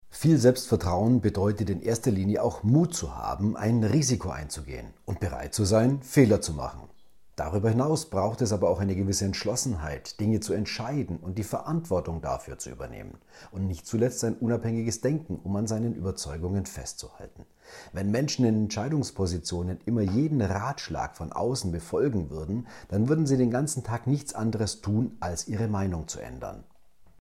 radioEXPERTEN - Ihr perfekter Interviewpartner